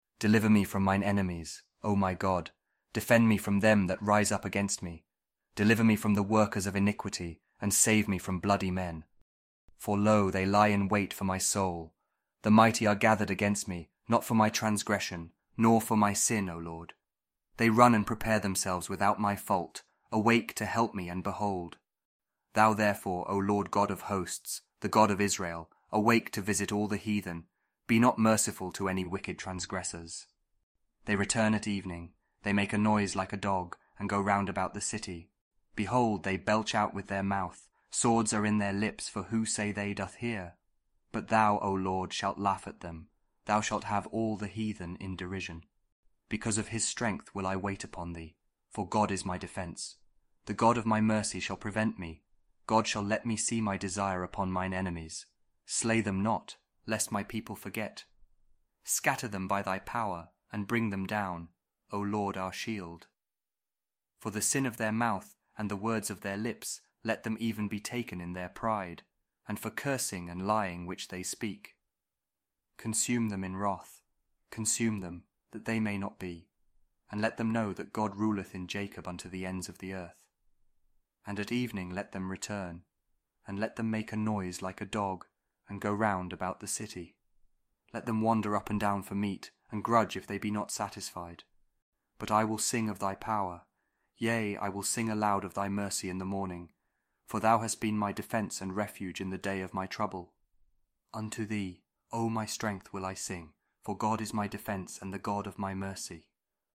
Psalm 59 | King James Audio Bible